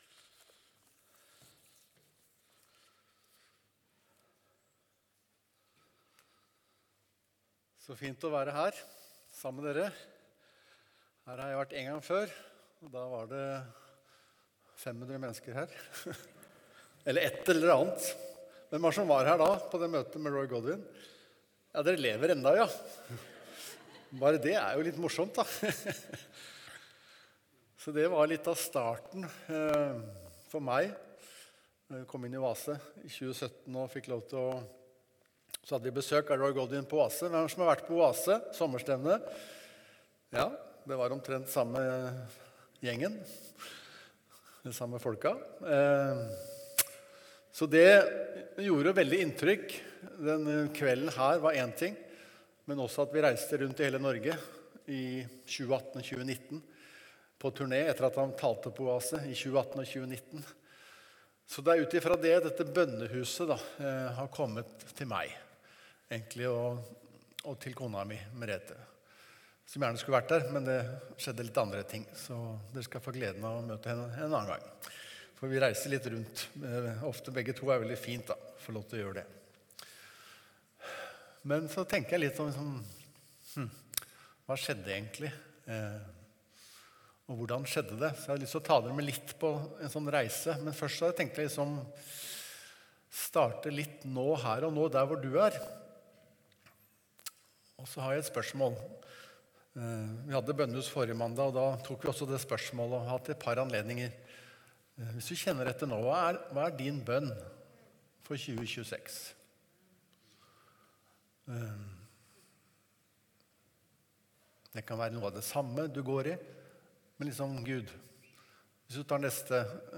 Evangeliehuset Porsgrunn Tale